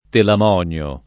telam0nLo] patron. mit. — Aiace T., figlio di Telamone — raro Telamonide [telamon&de]: e te piantato In su la nave, o re Telamonìde, Cantò [e tt% ppLant#to in Su lla n#ve, o r% ttelamon&de, kant0] (Carducci)